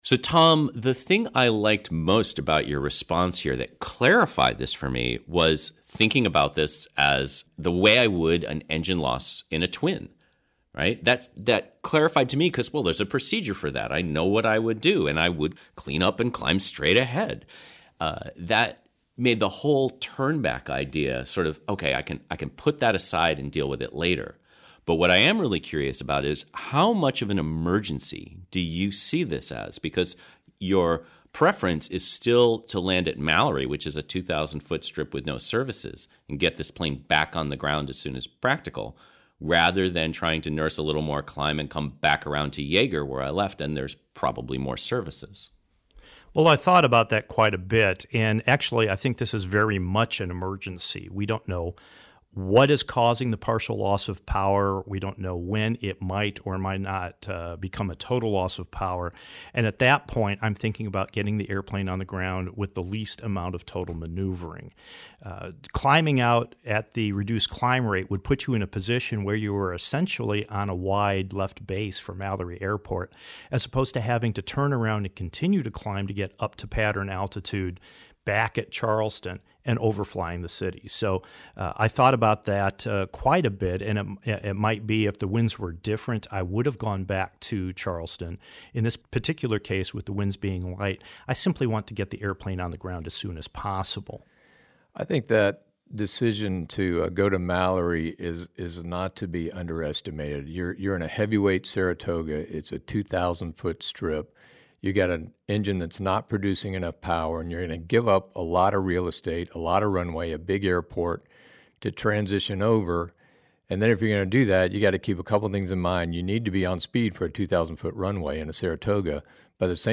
Crippled_Climb_Outof_Charleston_ Roundtable.mp3